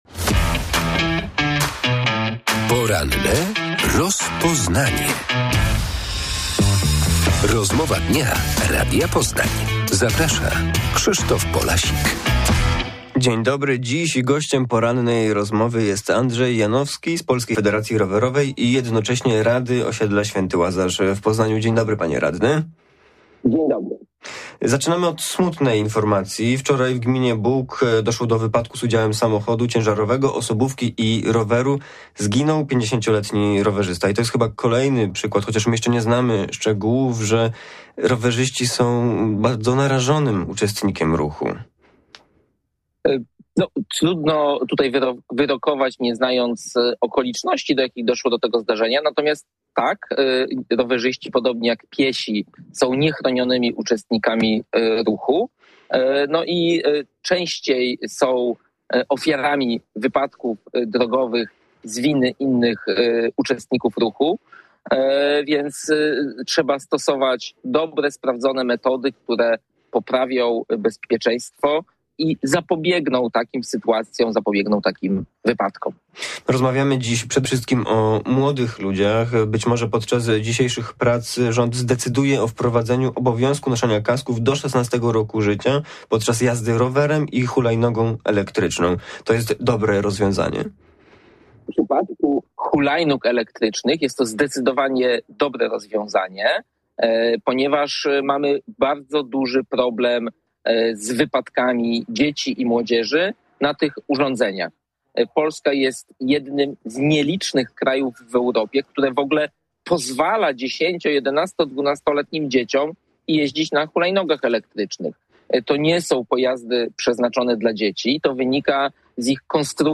Radio Poznań